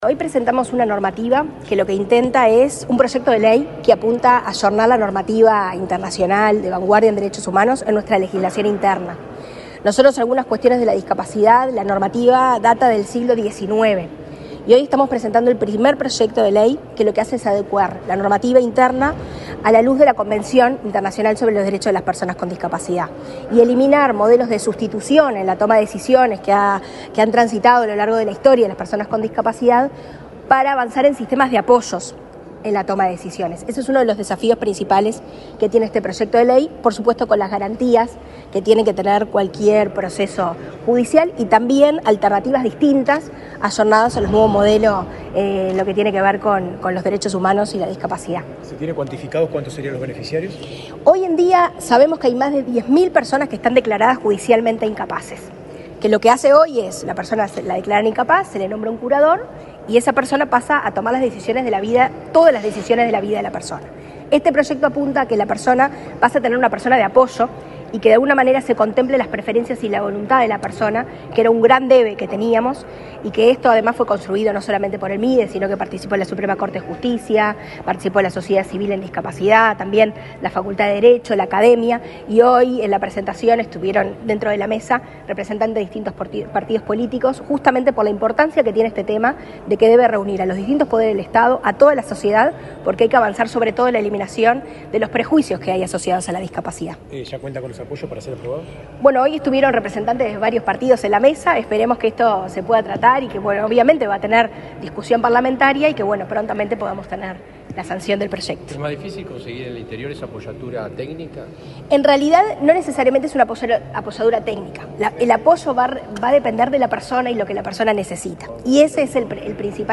Declaraciones de la directora de Discapacidad del Mides, Karen Sass
Este martes 23 en el Palacio Legislativo, la directora de Discapacidad del Ministerio de Desarrollo Social (Mides), Karen Sass, dialogó con la prensa, luego de participar en la presentación de un proyecto de ley para regular los apoyos y salvaguardias a fin de reconocer la capacidad jurídica de las personas con discapacidad en igualdad de condiciones.